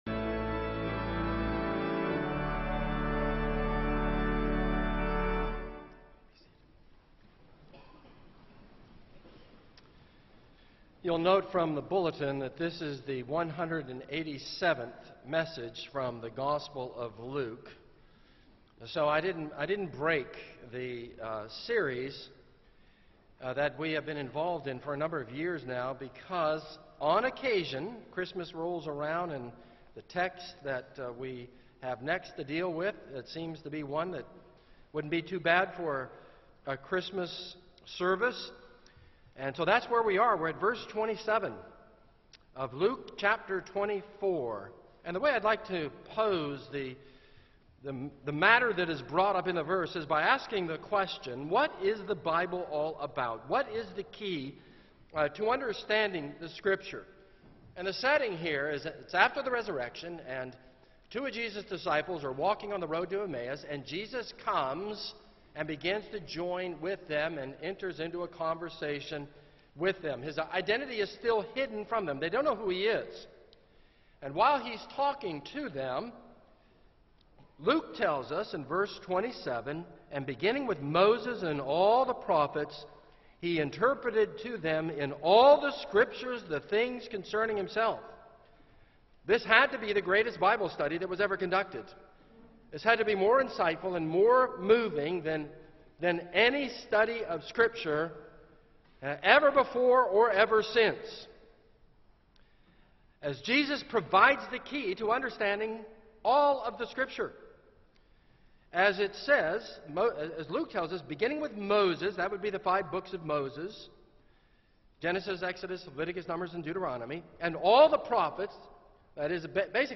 This is a sermon on Luke 24:27.